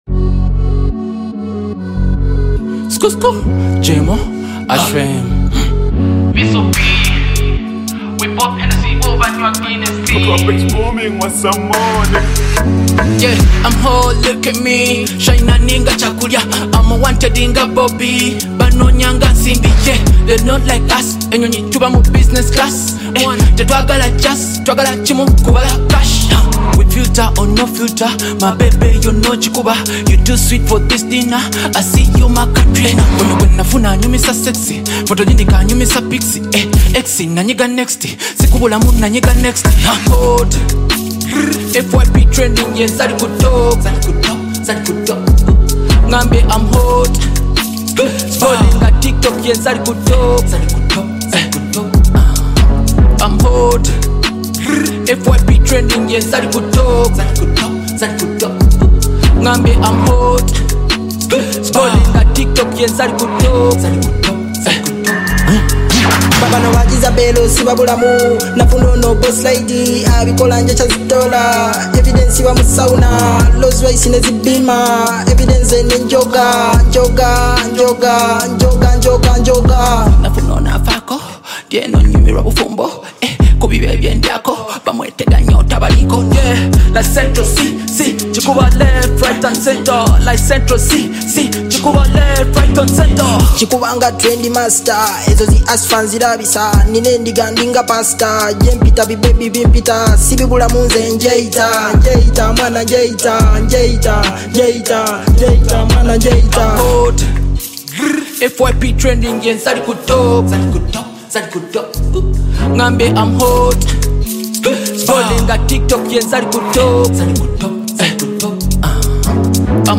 Genre: Rap Music